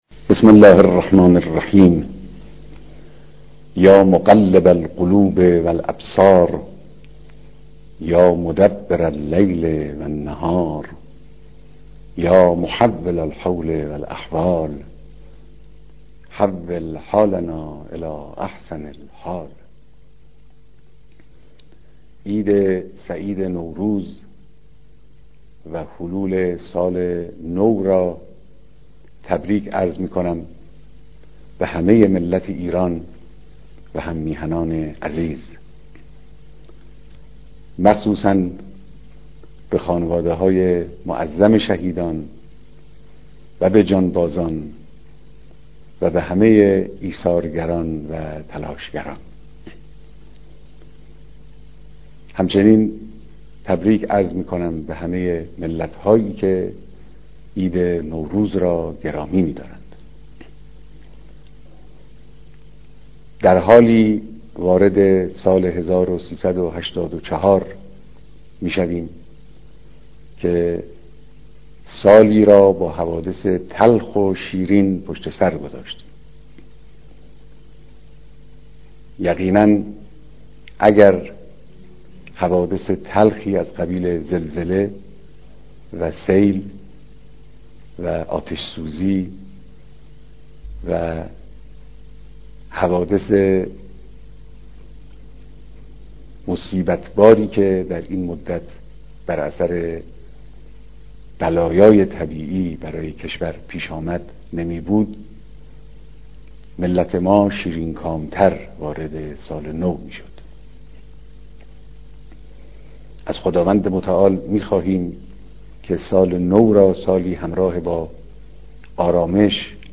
پيام مقام معظم رهبري به مناسبت حلول سال 1384